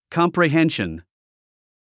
comprehension 発音 kɑ̀mprəhénʃen カンプラヘ ンション